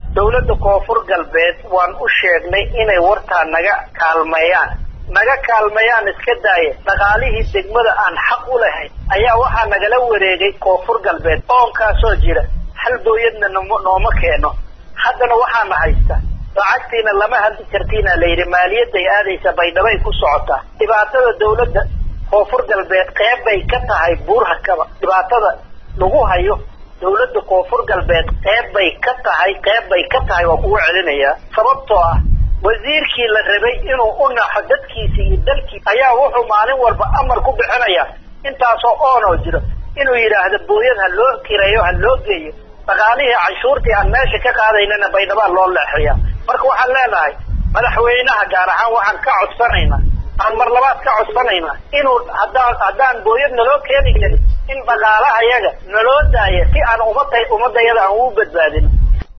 Gudoomiyaha Degmada Buurhakaba Maxamed Cabdi Maxamed oo la hadlay warbaahinta ayaa sheegay in dadka kunool degmada Buurhakabo ay soo wajahday xaalad aad u adag ka dib markii ay gureen warihii biyaha ay ka heli jireen shacabka kunool degmada Buurhakaba.